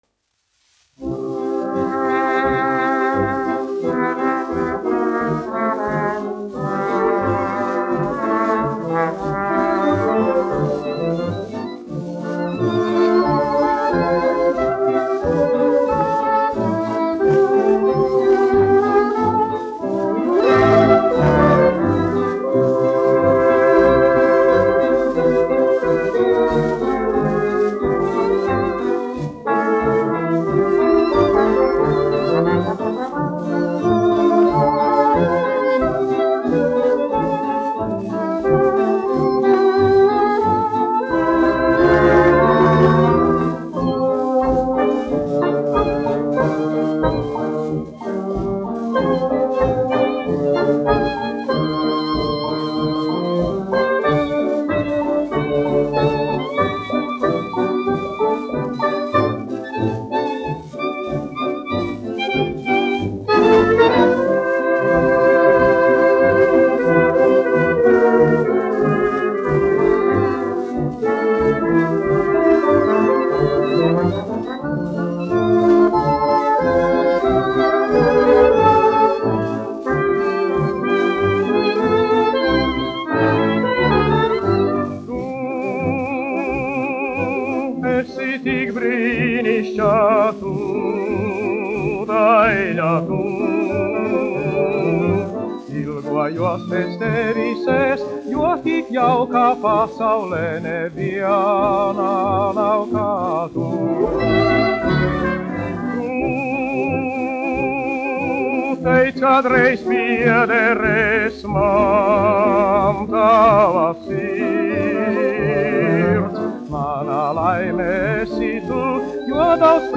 1 skpl. : analogs, 78 apgr/min, mono ; 25 cm
Fokstroti
Kinomūzika
Skaņuplate